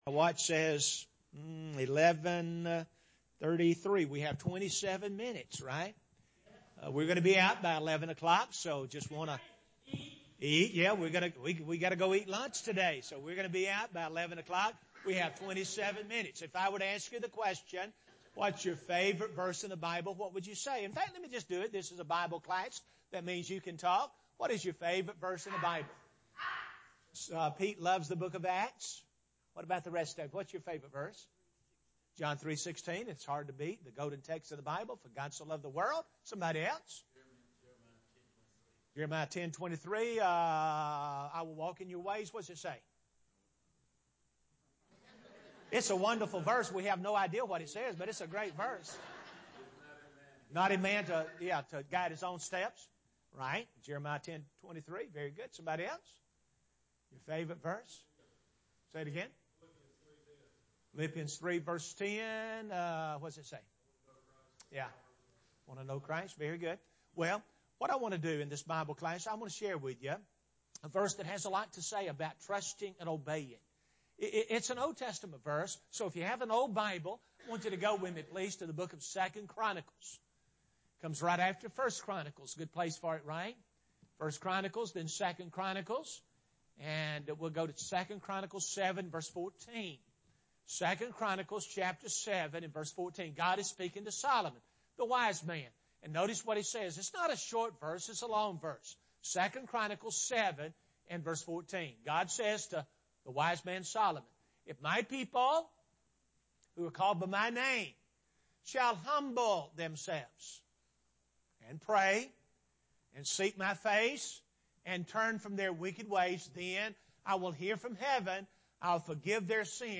Class: Trust and Obey
Gospel Meeting